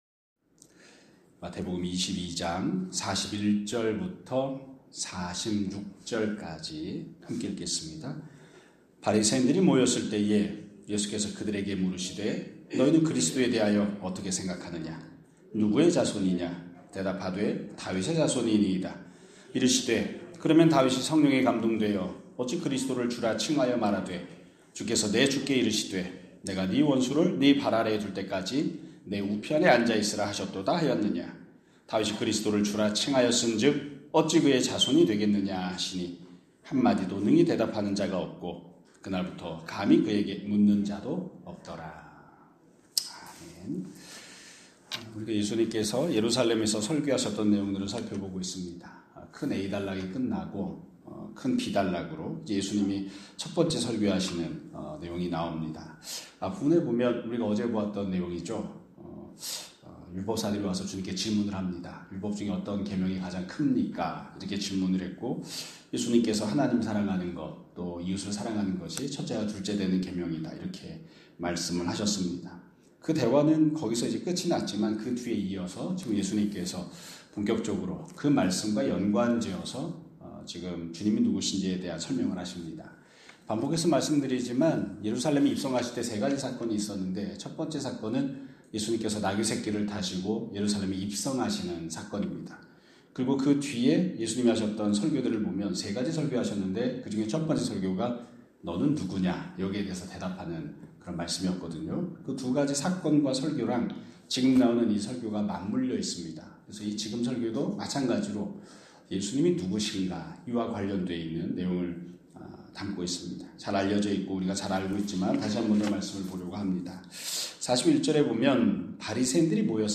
2026년 2월 13일 (금요일) <아침예배> 설교입니다.